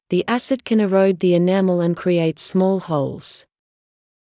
ｼﾞ ｱｼｯﾄﾞ ｷｬﾝ ｲﾛｰﾄﾞ ｼﾞ ｴﾅﾓｳ ｴﾝﾄﾞ ｸﾘｴｲﾄ ｽﾓｰﾙ ﾎｰﾙｽﾞ